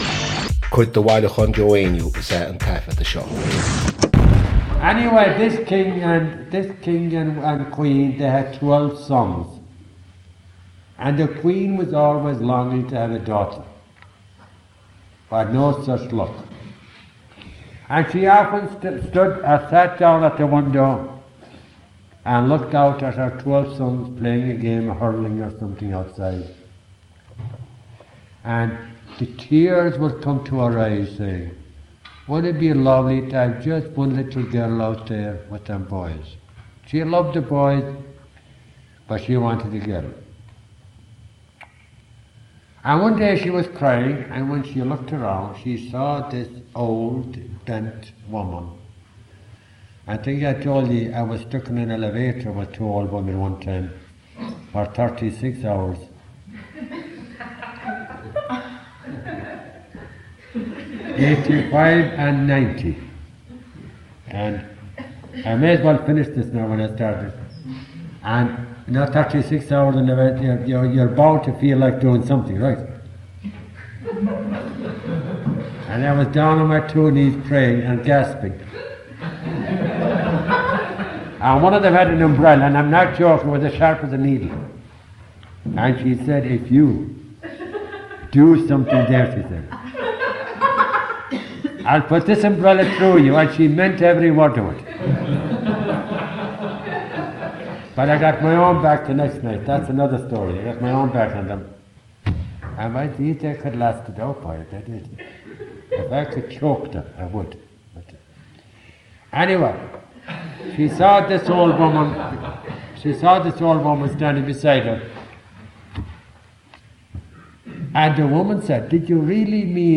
Catagóir (Category) : story.
Suíomh an taifeadta (Recording Location) : University of Pennsylvania, United States of America. Ocáid an taifeadta (Recording Occasion) : evening class.